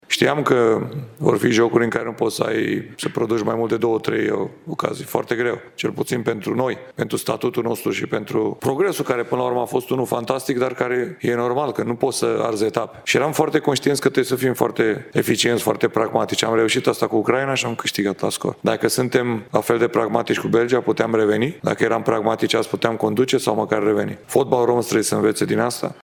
Antrenorul naționalei, după meciul de marți seară, 2 iulie: